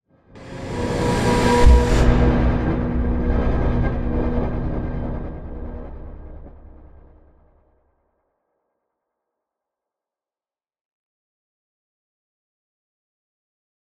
conjuration-magic-sign-rune-intro-fade.ogg